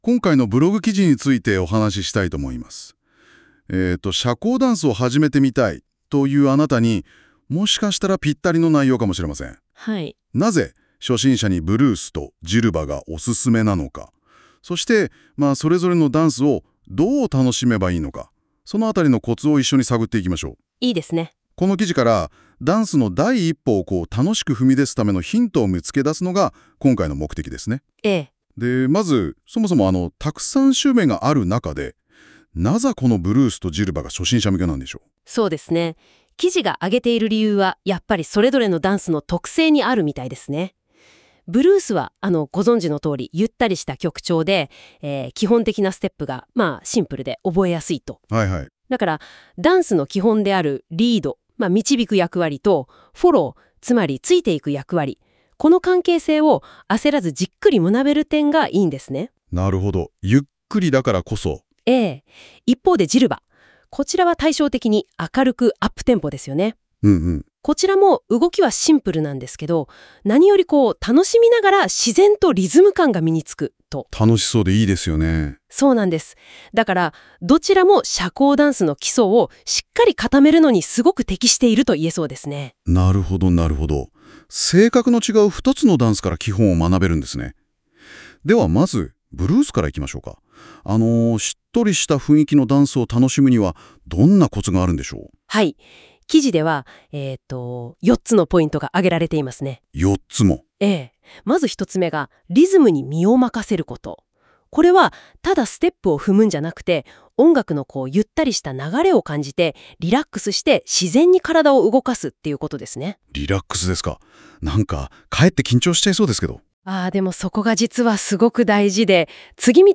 どちらも基本的な動きが多く、ダンスの基礎を楽しみながら学べるので、初心者にぴったりです！ このブログについて、AIで生成した会話音声（約7分）も、お楽しみいただけます。